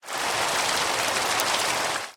ambient / weather / rain4.ogg
rain4.ogg